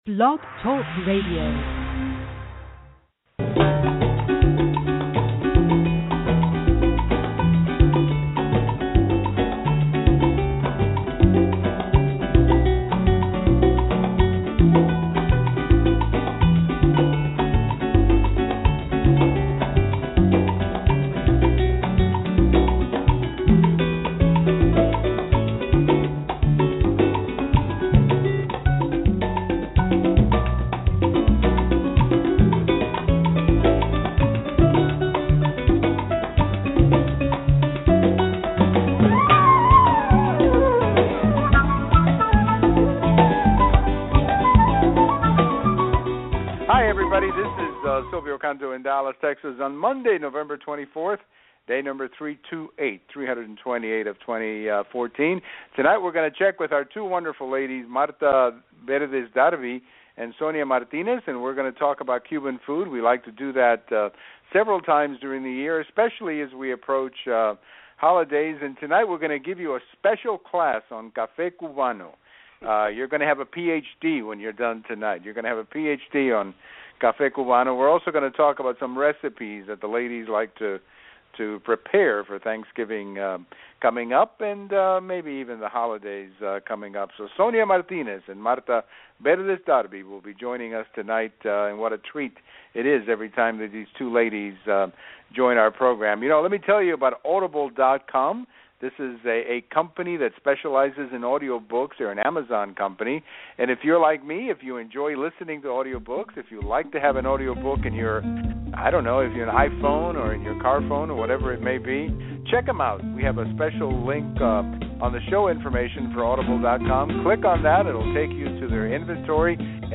A discussion of current events without screaming or name calling!